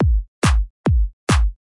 140个循环 " 140个BP M ClapHH Sync
描述：用Fruity Loops制作的140个基本循环
Tag: 140 FL 恍惚